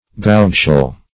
Search Result for " gougeshell" : The Collaborative International Dictionary of English v.0.48: Gougeshell \Gouge"shell`\, n. (Zool.) A sharp-edged, tubular, marine shell, of the genus Vermetus ; also, the pinna.